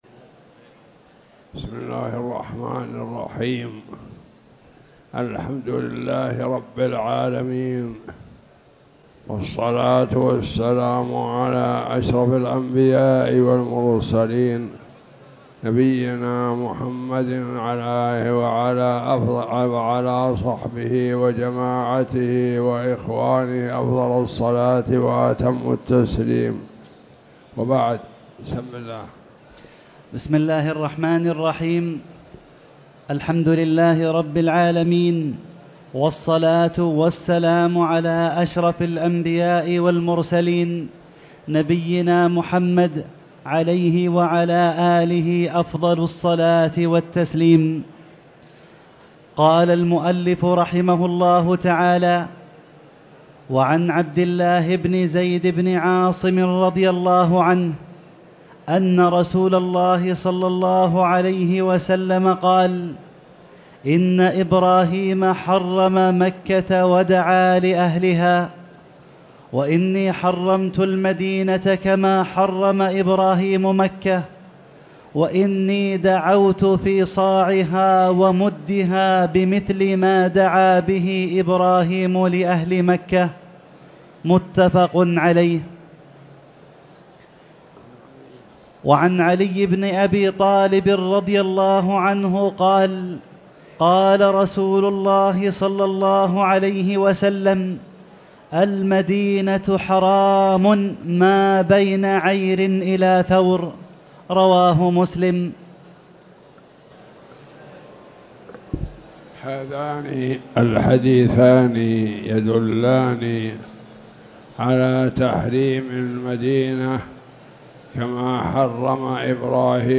تاريخ النشر ١٢ رجب ١٤٣٨ هـ المكان: المسجد الحرام الشيخ